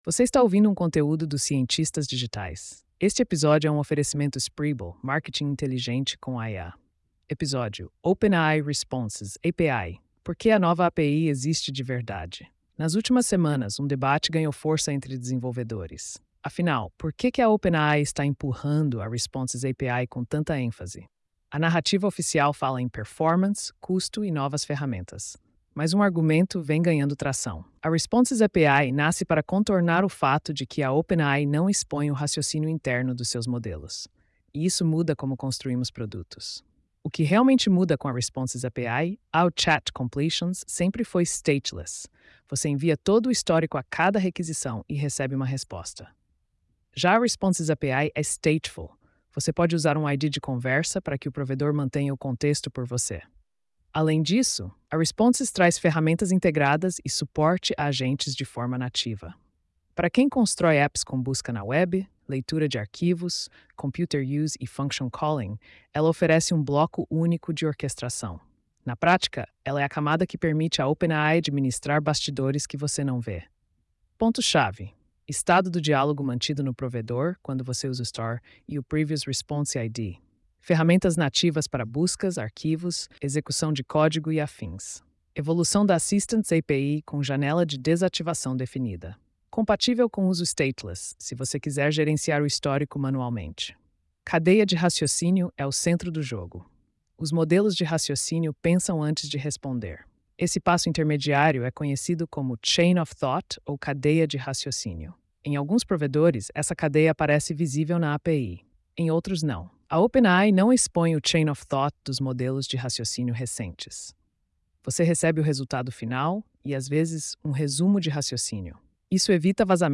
post-4360-tts.mp3